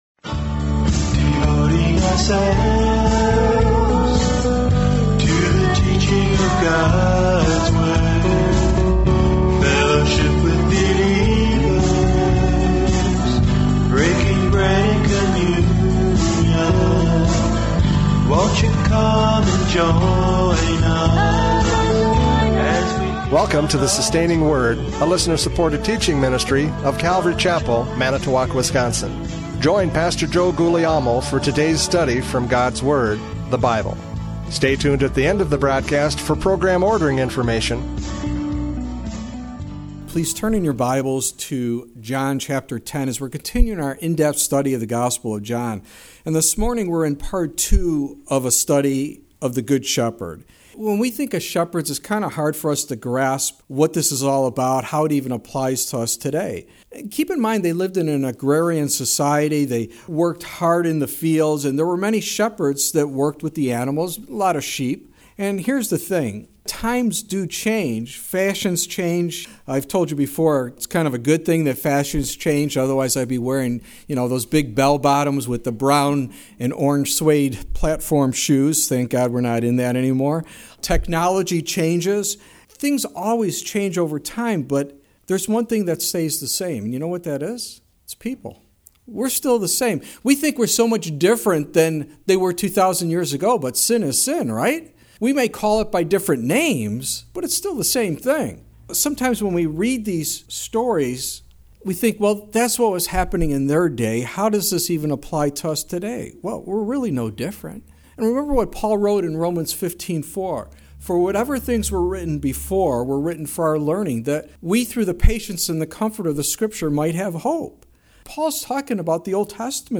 John 10:11-21 Service Type: Radio Programs « John 10:1-10 The Good Shepherd!